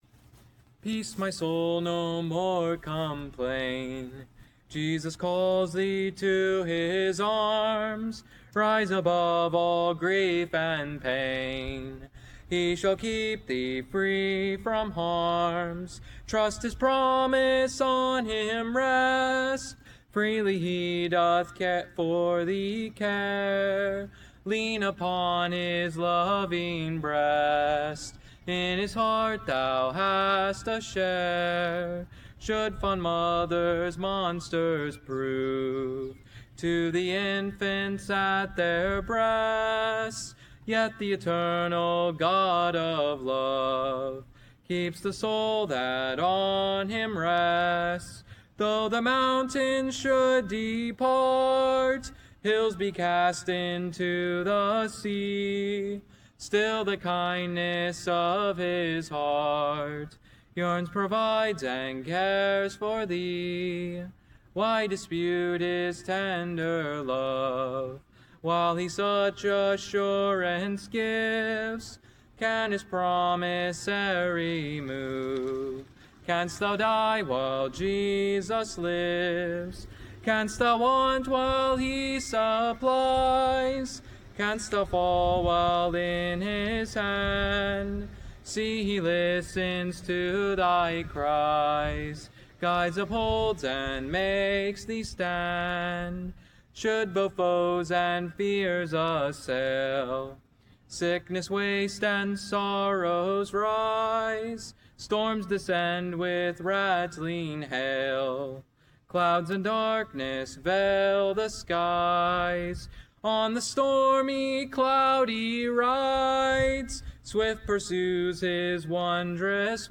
Here is the audio portion of the song singing: